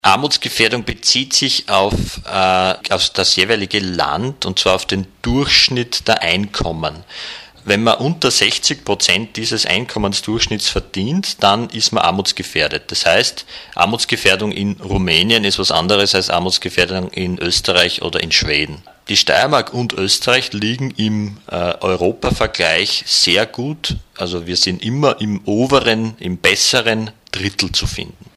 im O-Ton